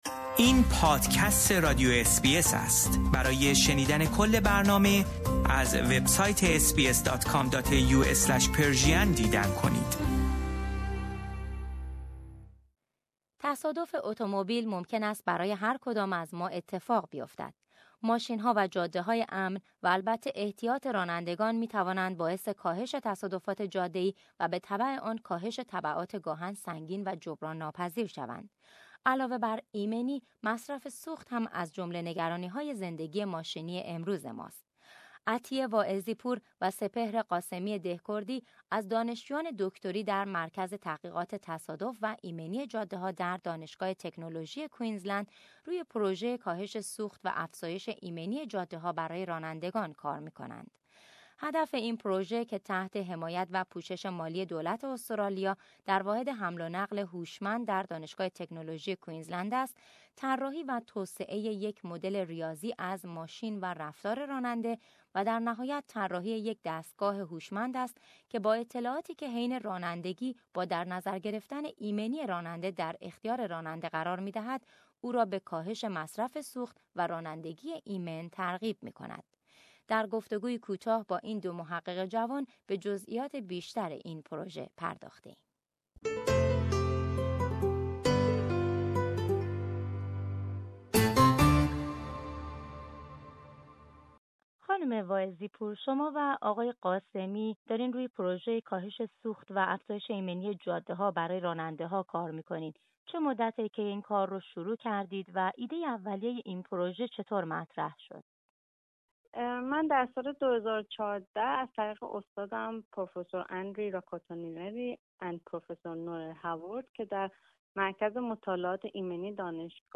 در گفتگویی کوتاه با این دو محقق جوان به جزئیات بیشتر این پروژه پرداخته ایم.